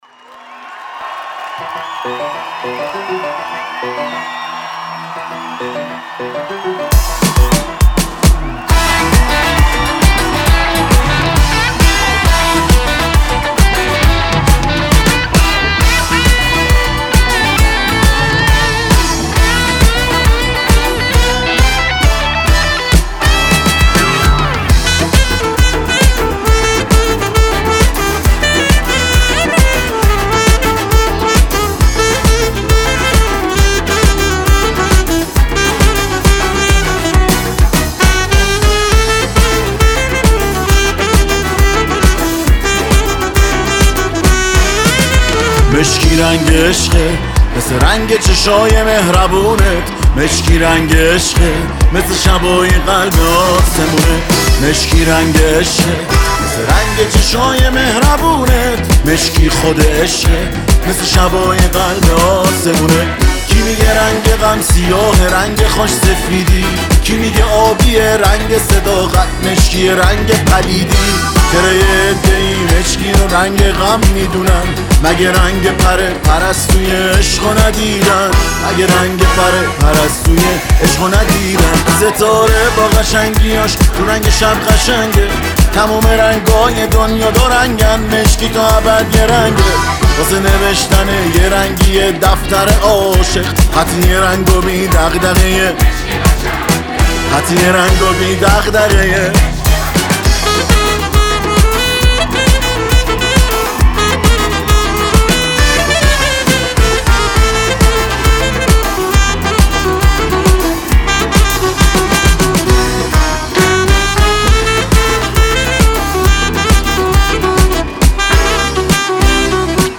ورژن زنده